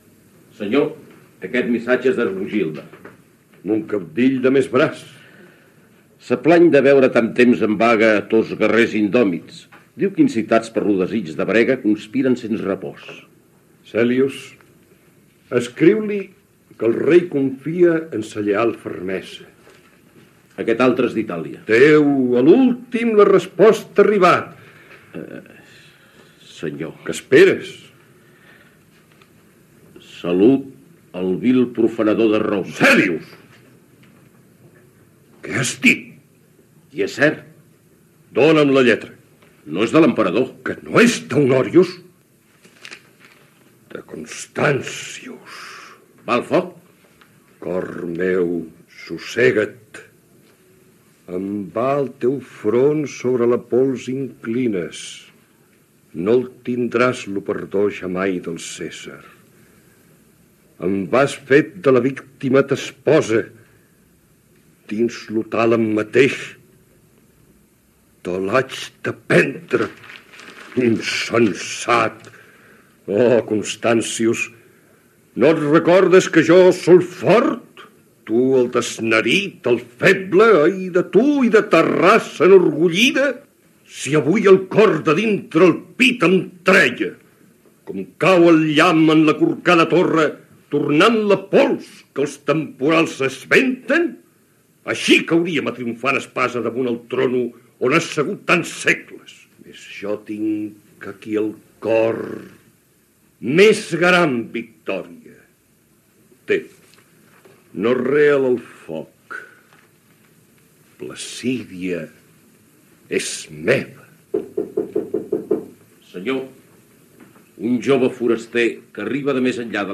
Adaptació radiofònica de l'obra "Gal·la Placídia" d'Àngel Guimerà.
Ficció